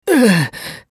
男性
厨二病ボイス～戦闘ボイス～
【ダメージ（中）2】